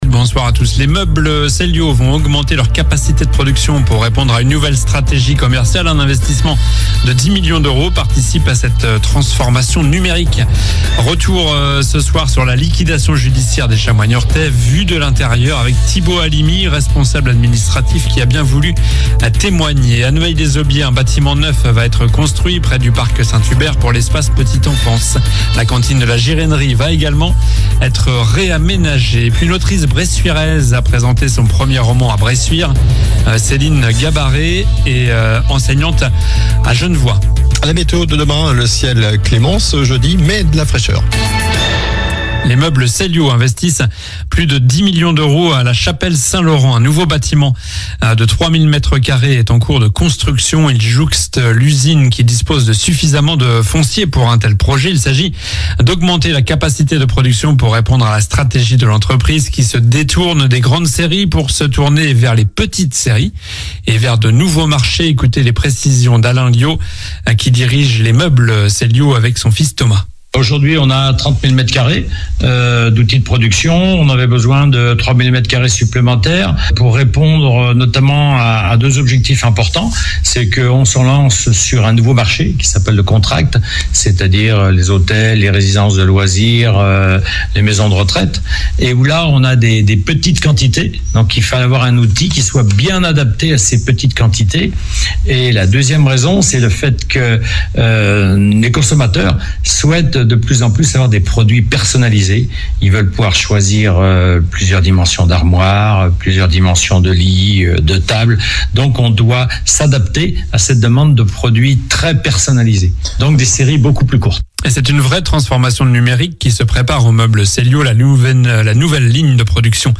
Journal du mercredi 1 1 septembre (soir)